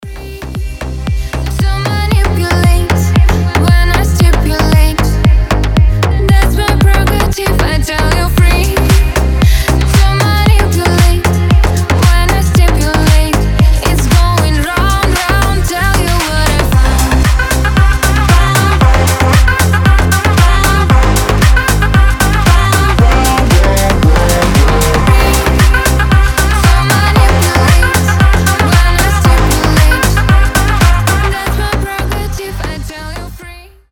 • Качество: 320, Stereo
deep house
Club House
Cover